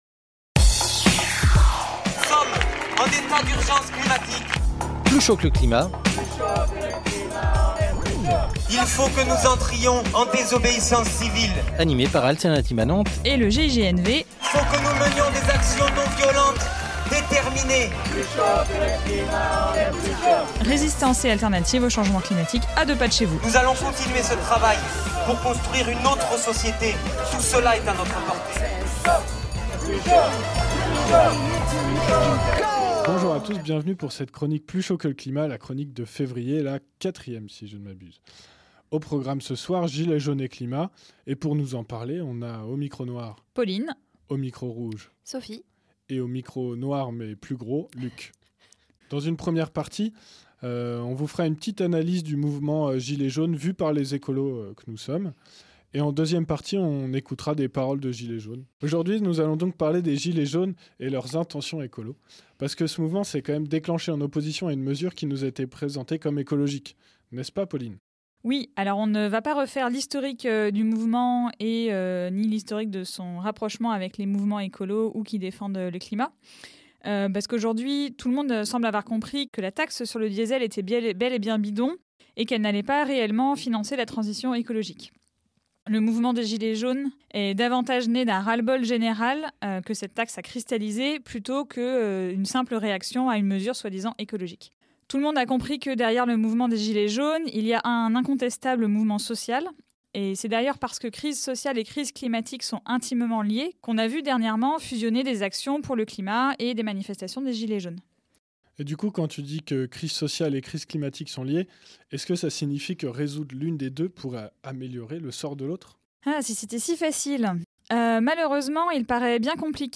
Chroniques